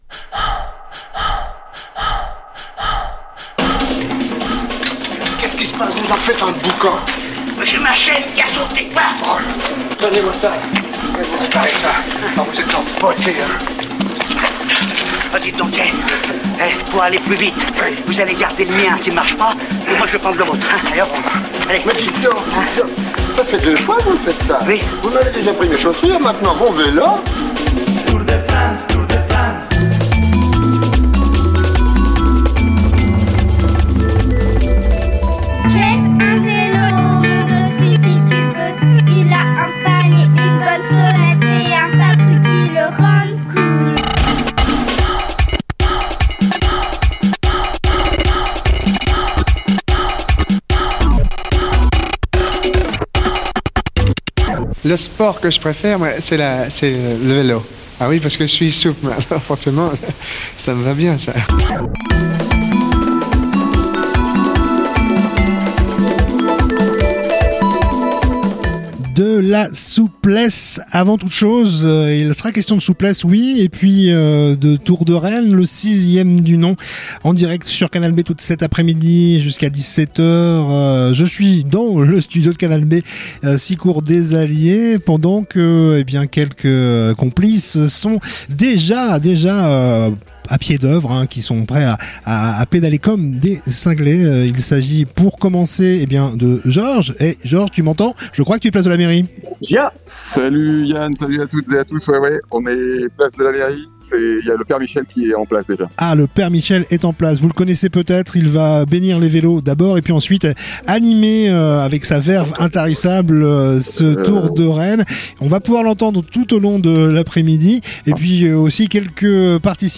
Emission spéciale